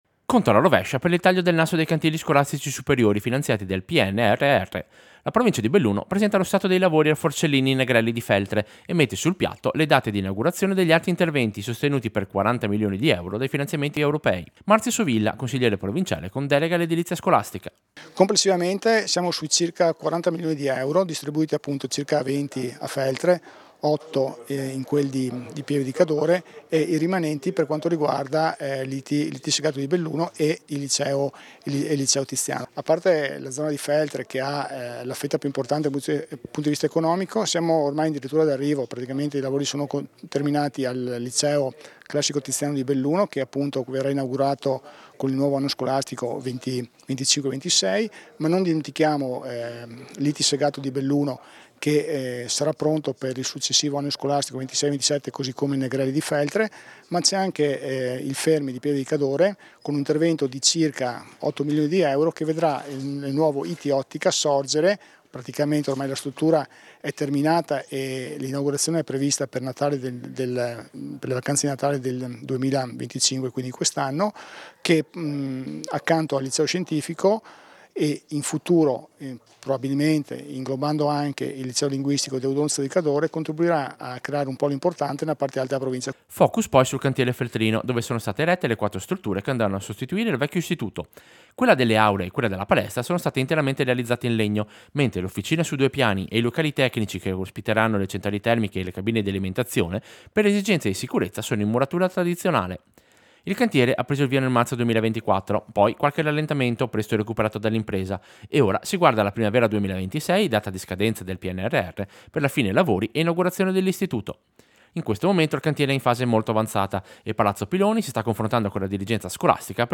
Servizio-Sopralluogo-cantiere-Negrelli.mp3